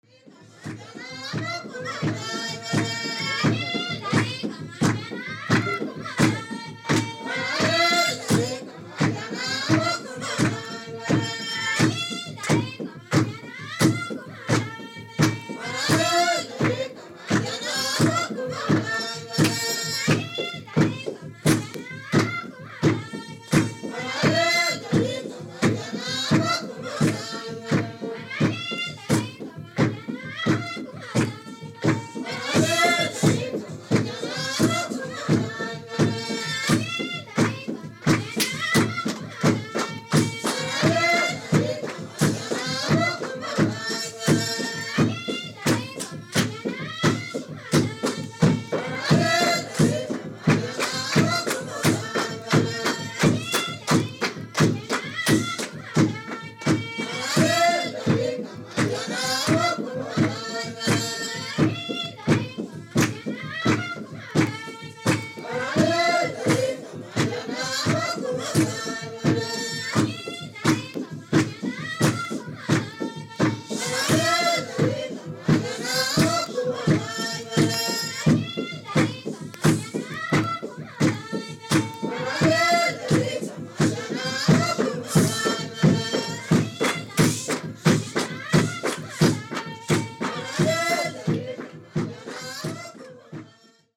エリトリア・主要９民族（アファル族、ビレン族、ヘダレブ族、クナマ族、ナラ族、ラシャイダ族、サホ族、ティグレ族、ティグリニャ族）
エチオピアでまとめるには奥の深いサウンドです！！！